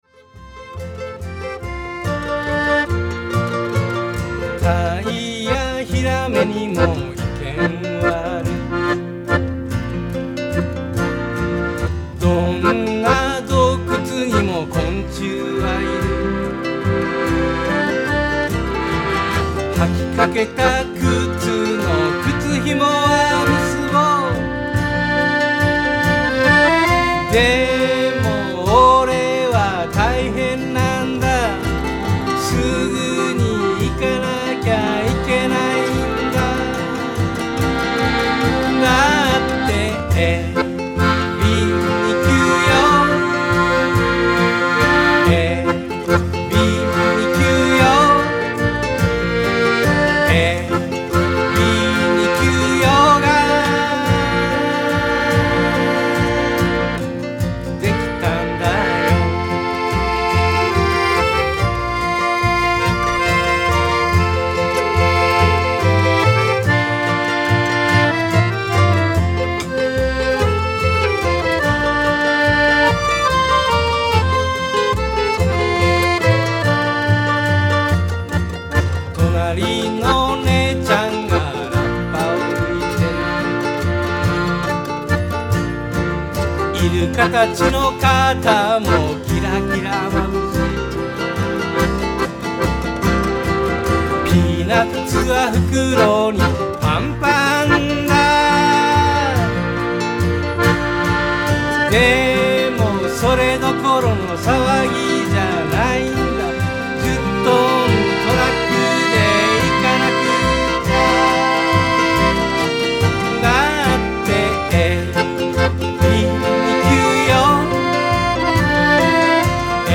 vo，g
piano，accordion